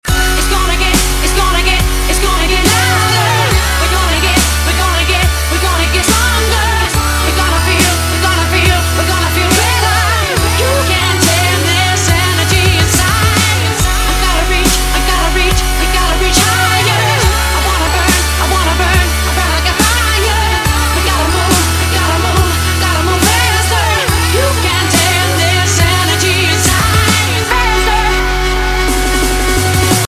Dance & Trance